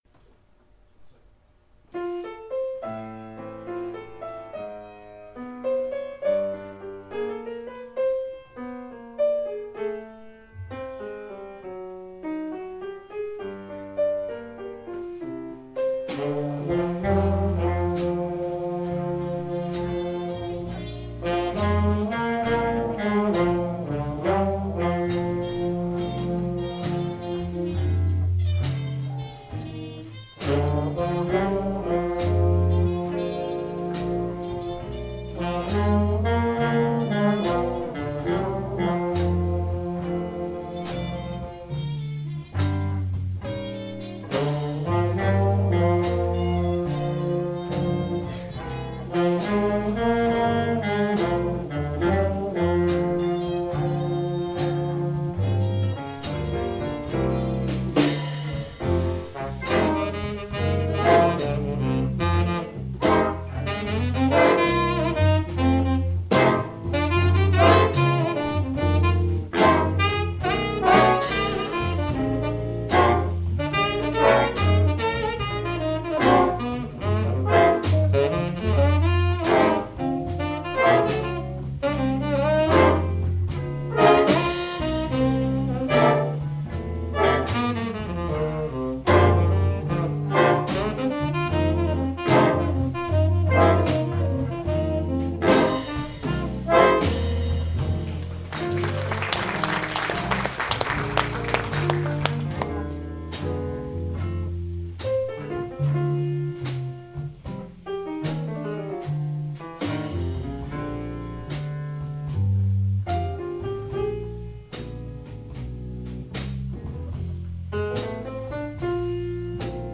Galleri - Jazz in a Blue Funky Space oktober 2014
- Hayburner Big Band 26. oktober 2014